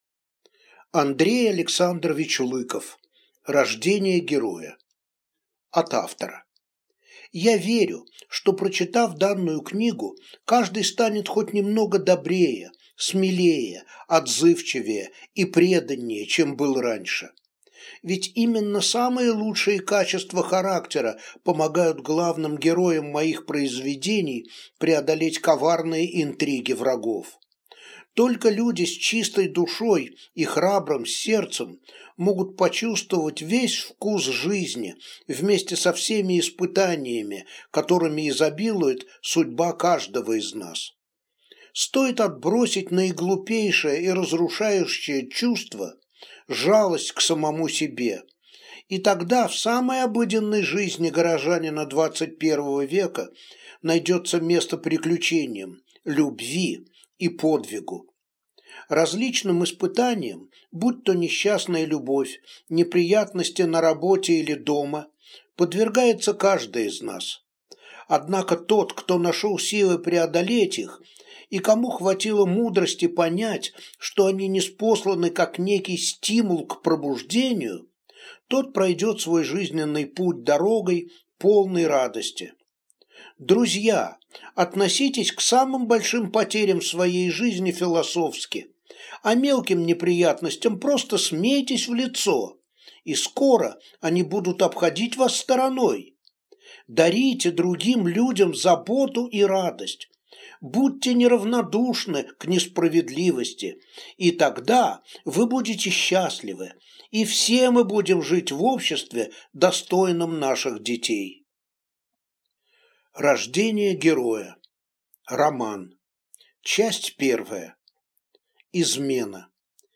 Аудиокнига Рождение героя